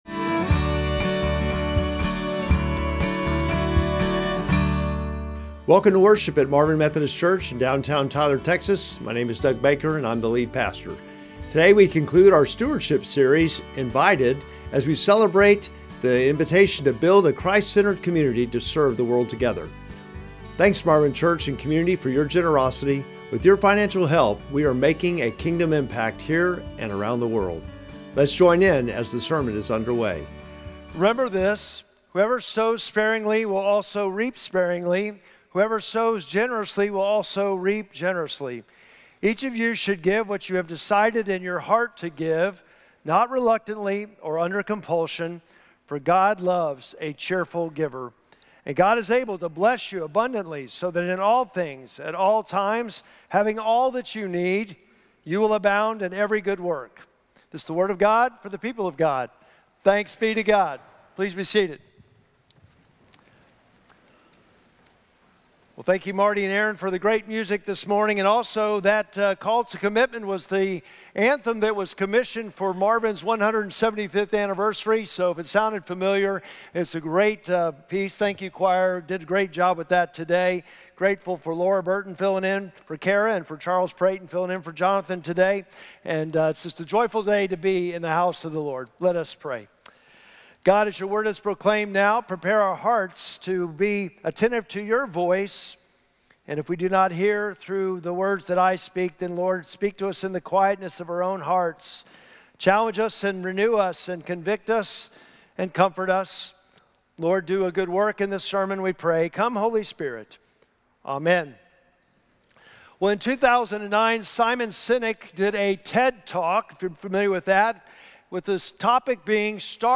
Sermon text: 2 Corinthians 9:6-8